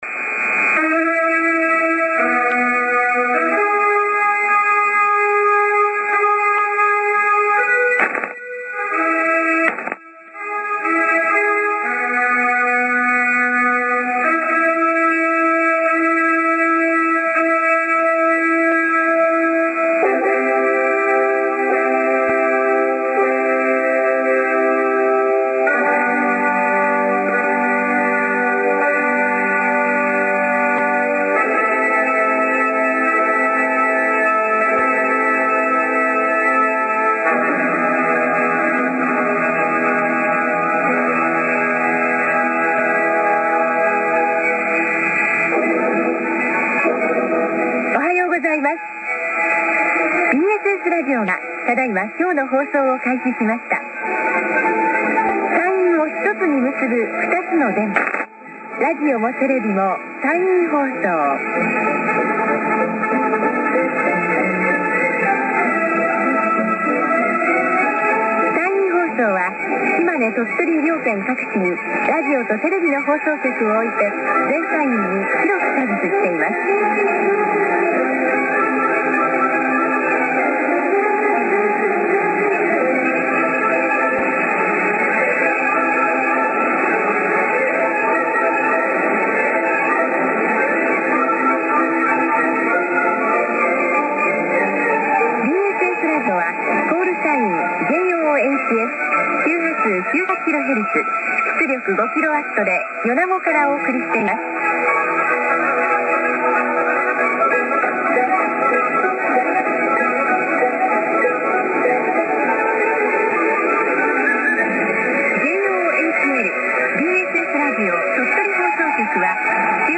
民放の開始と終了アナウンス集
録音環境　・受信場所：尾張旭市　・アンテナ：ＡＬＡ１５３０　・受信機：ＡＯＲ７０３０Ｐlus,ＪＲＣＮＲＤ−５４５